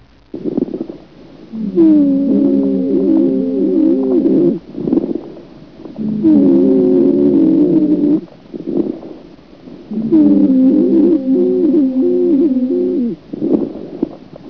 Listen to the lung sounds and try to identify with adventitious breath sounds (Table 32-3 on page 853) and we will discuss in class.
lungsound3.aif